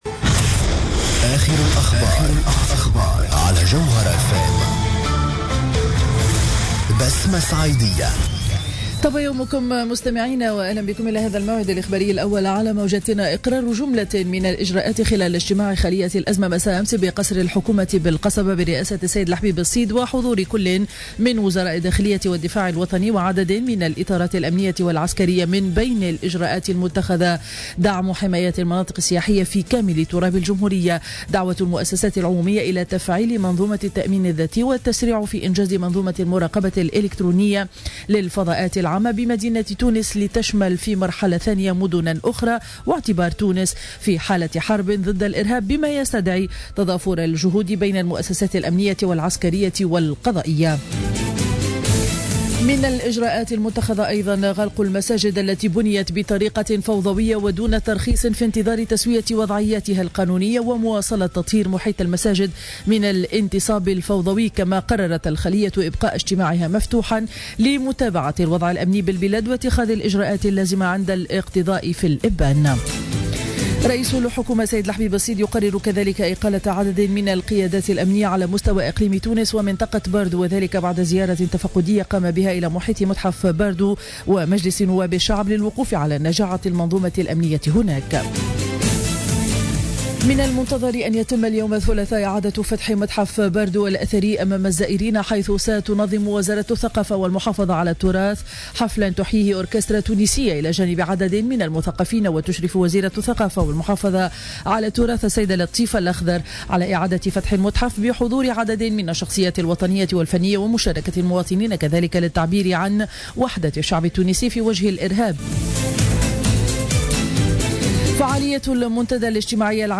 نشرة أخبار السابعة صباحا ليوم الثلاثاء 24 مارس 2015